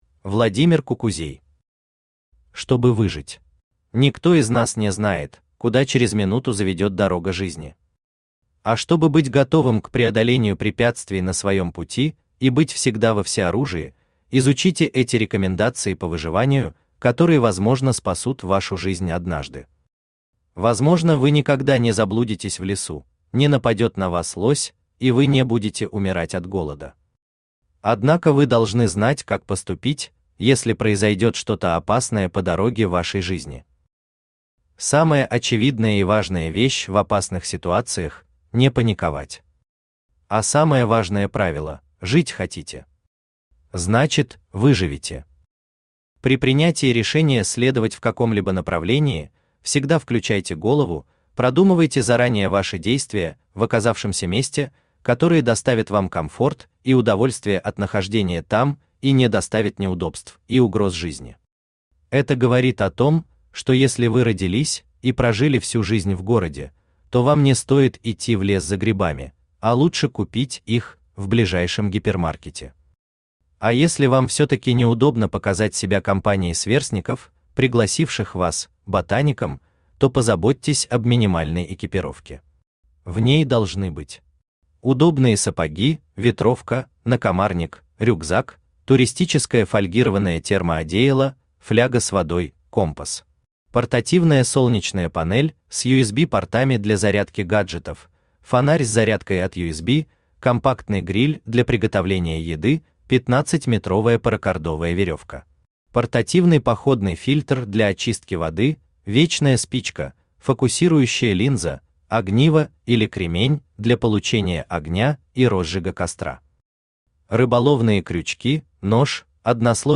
Аудиокнига Чтобы выжить | Библиотека аудиокниг
Aудиокнига Чтобы выжить Автор Владимир Николаевич Кукузей Читает аудиокнигу Авточтец ЛитРес.